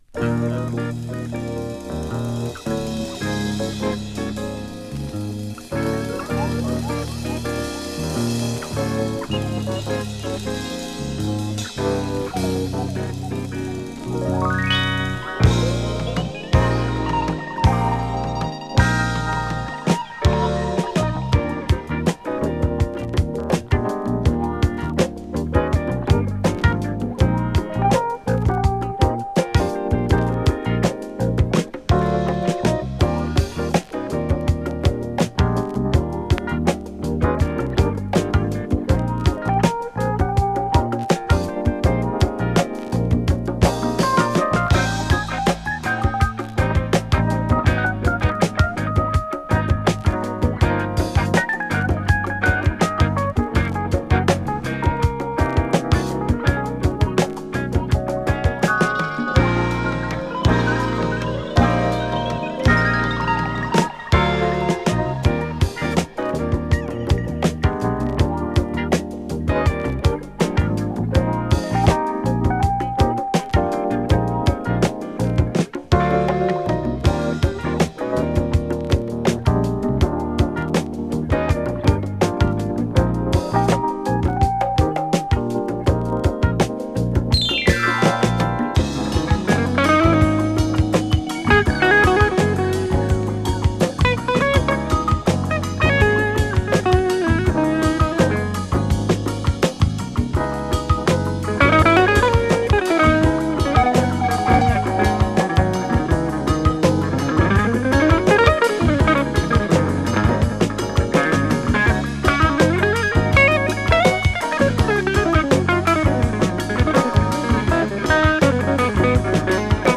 失神エレピ・メロウグルーヴ